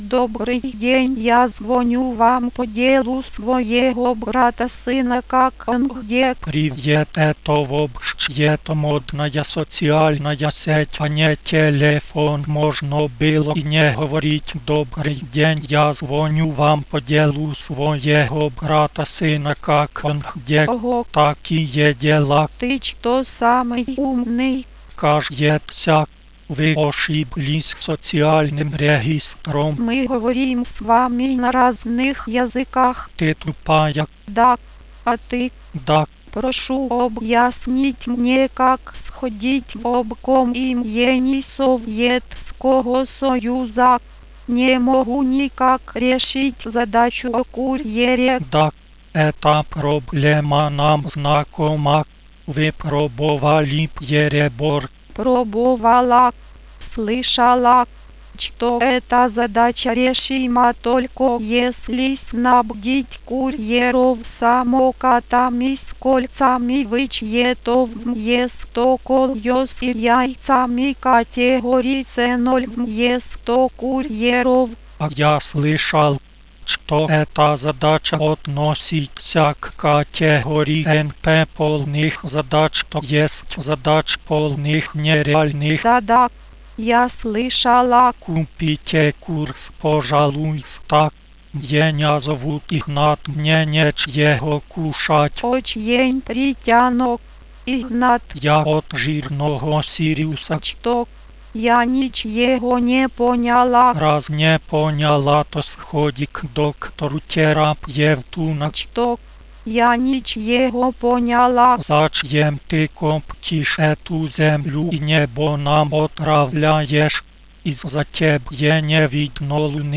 В нём велись разговоры да беседы.
подслушать разговоры из клуба теперь может каждый.